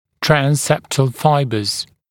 [træn’septl ‘faɪbəz][трэн’сэптл ‘файбэз]межзубные волокна, транссептальные волокна